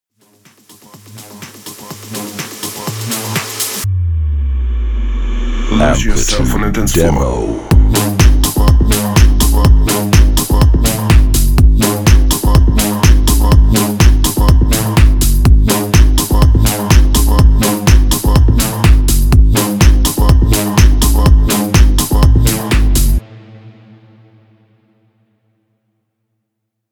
124 BPM  A♭ major  4B
Tech House